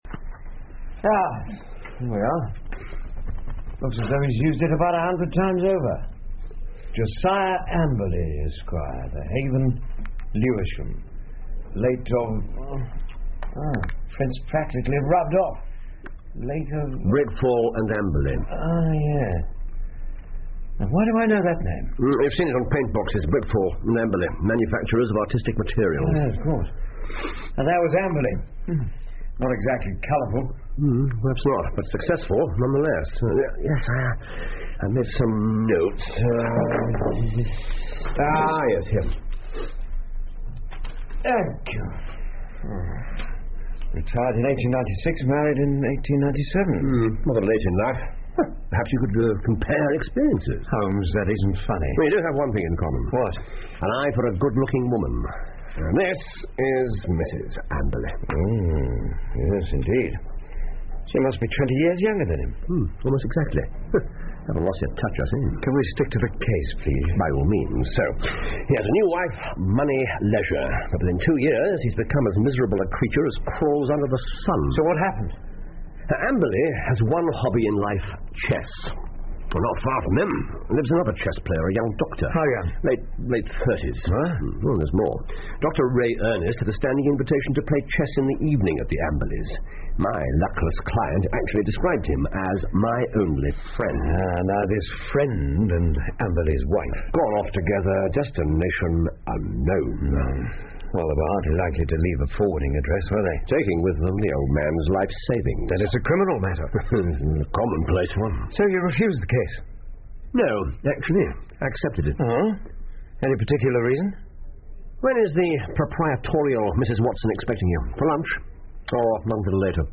福尔摩斯广播剧 The Retired Colourman 3 听力文件下载—在线英语听力室